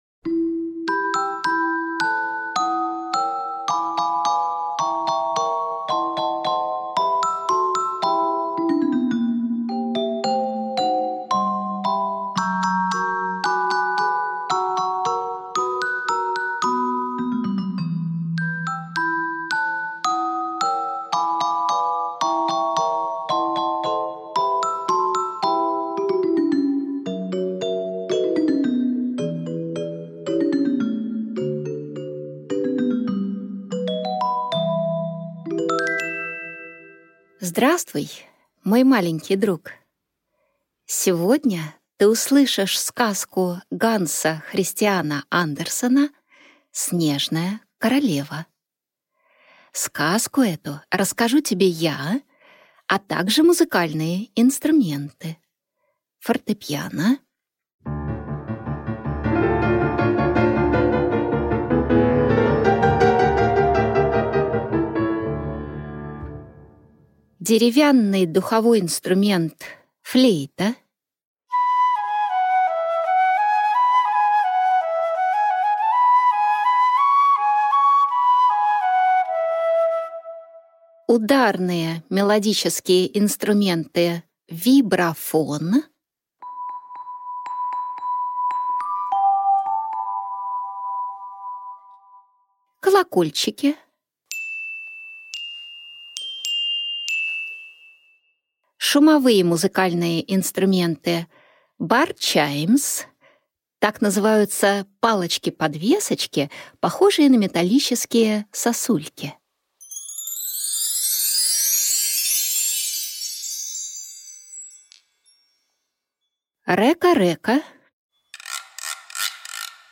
Аудиокнига Снежная королева | Библиотека аудиокниг
Прослушать и бесплатно скачать фрагмент аудиокниги